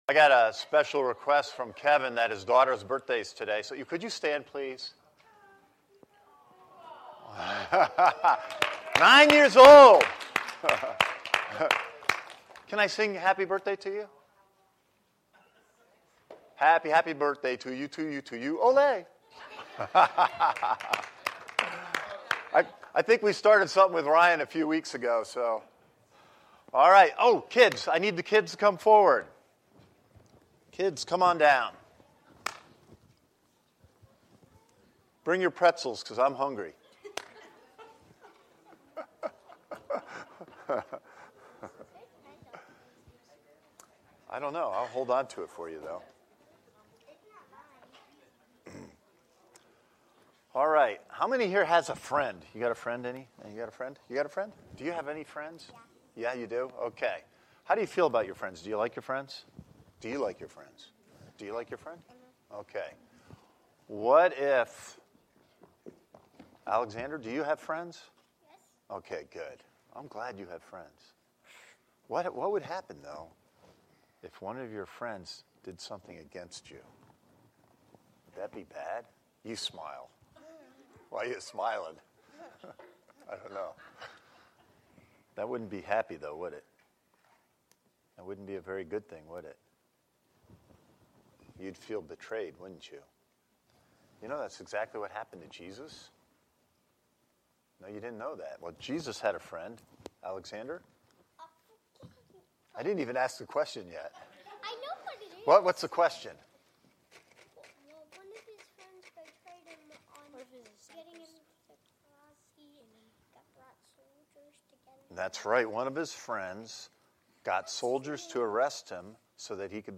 This is the main Sunday Service for Christ Connection Church